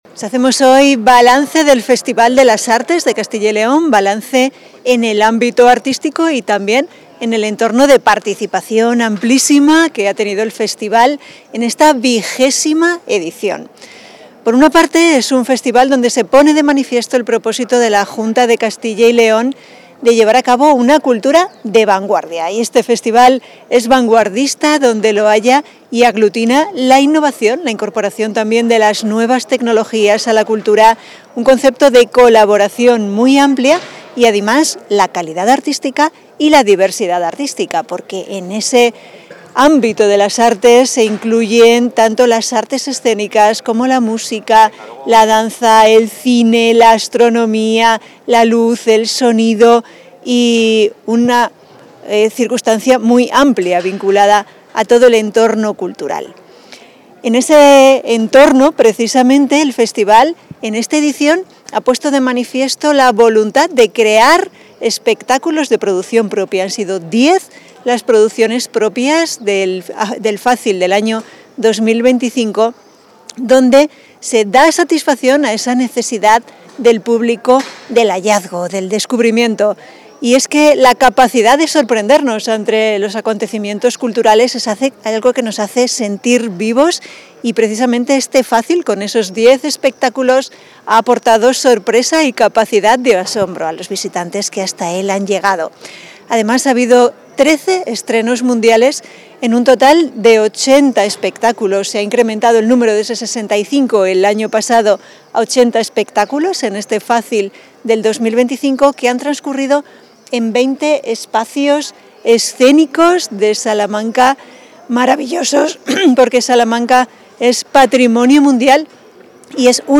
Valoración de la viceconsejera de Acción Cultural.